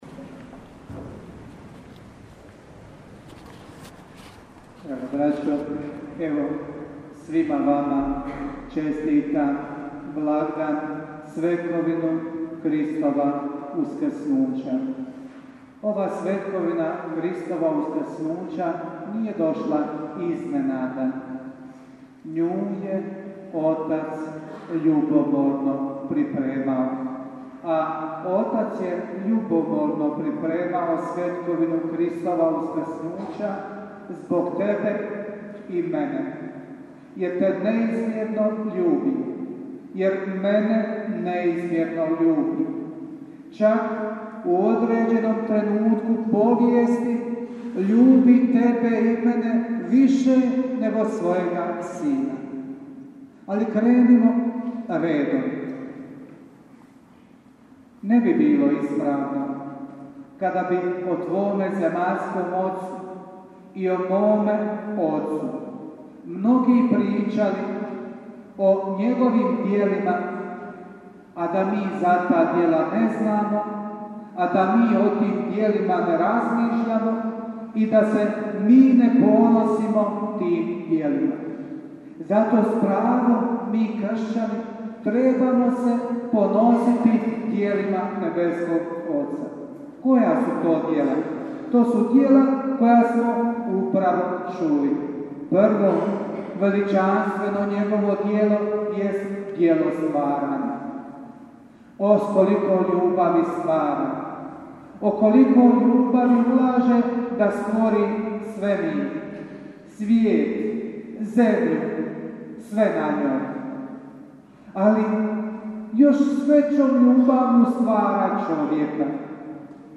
PROPOVIJED-VAZMENO BDIJENJE :